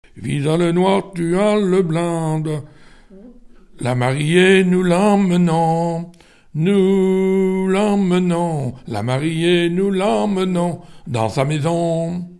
Genre laisse
Pièce musicale inédite